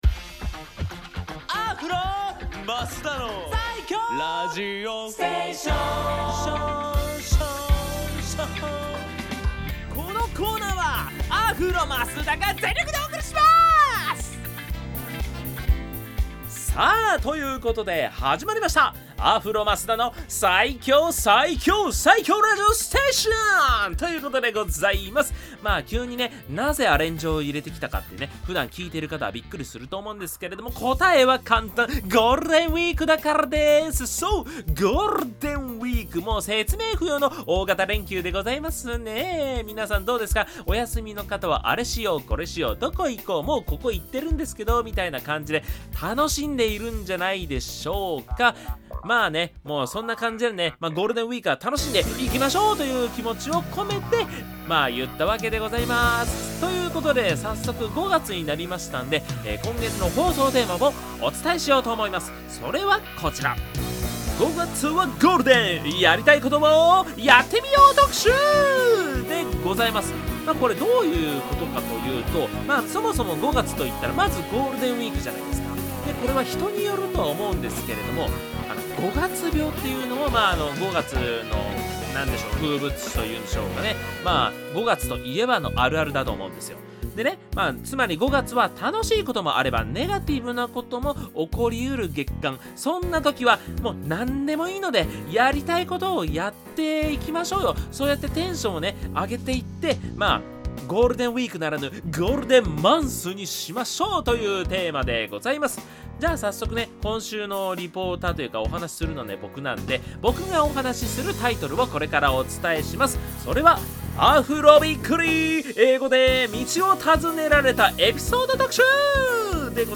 こちらのブログでは、FM83.1Mhzレディオ湘南にて放送されたラジオ番組「湘南MUSICTOWN Z」内の湘南ミュージックシーンを活性化させる新コーナー！
こちらが放送音源です♪